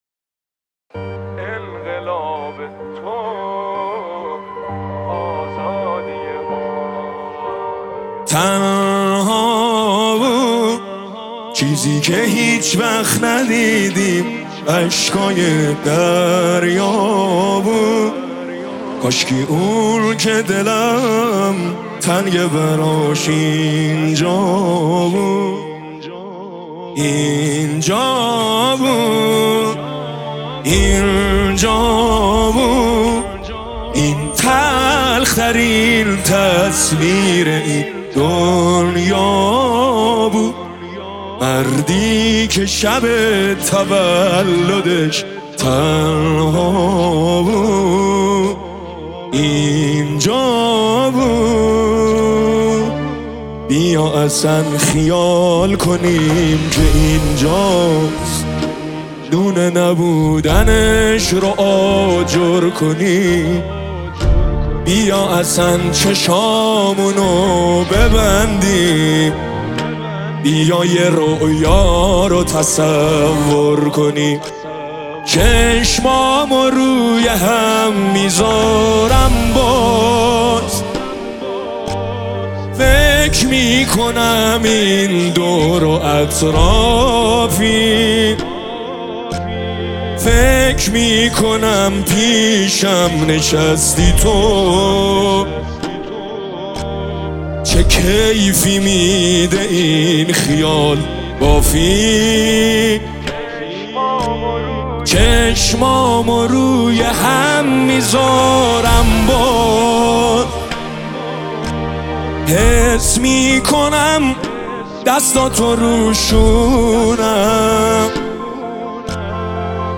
نماهنگ احساسی و دلنشین